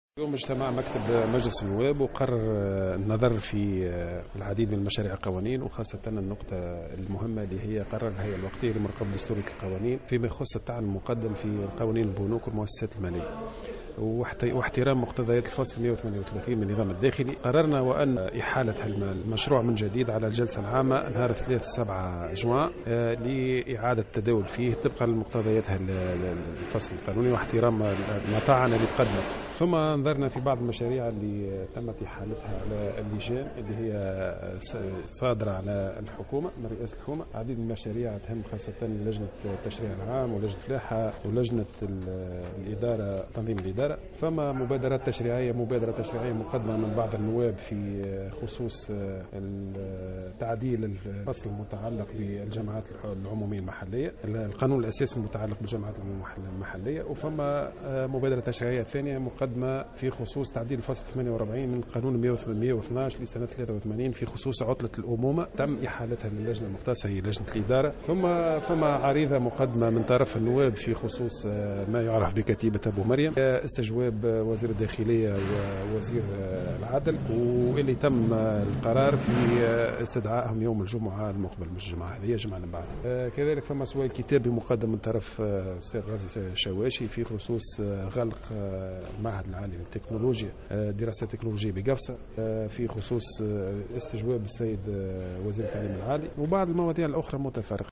أكد الناطق باسم المكتب منجي الحرباوي في تصريح لمراسل الجوهرة اف ام اليوم الإثنين...